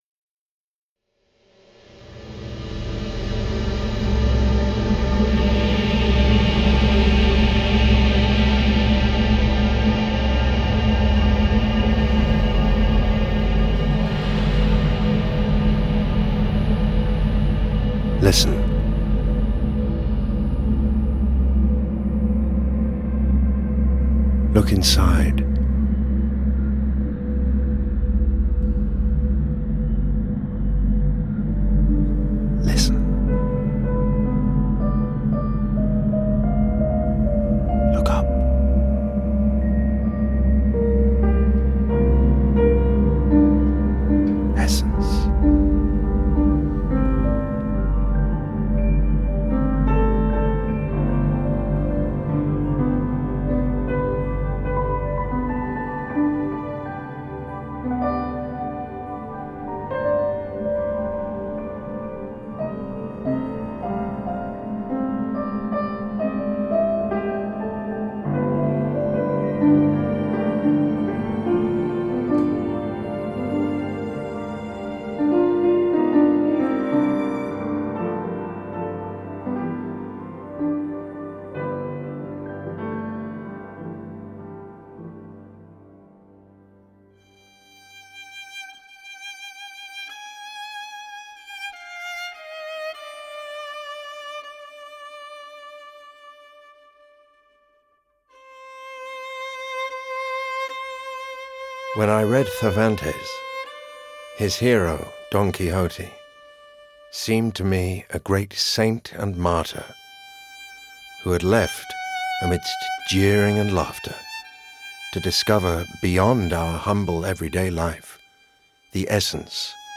An award-winning meditation on creativity, life and love featuring a full soundtrack and original recordings of classical masterpieces.
You can now listen to the podcast version of Essence: f ull audio track including voice over and music.